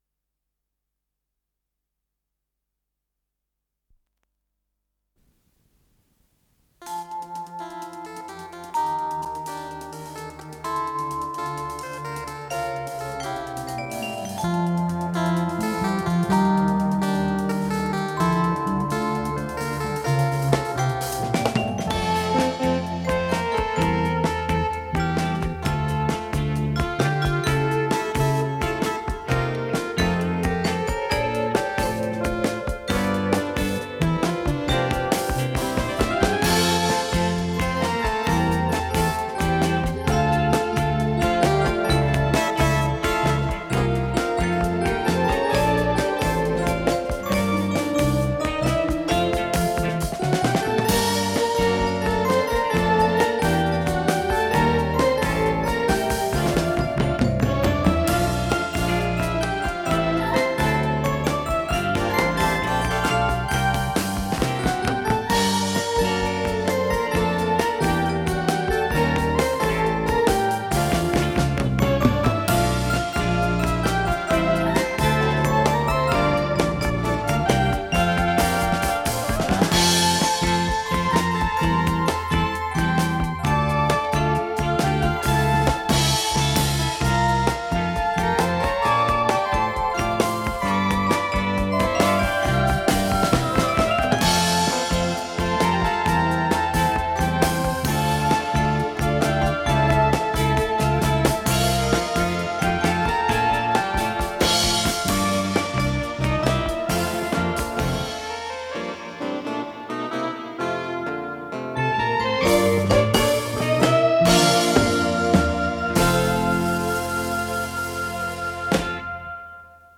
Скорость ленты38 см/с
Тип лентыORWO Typ 106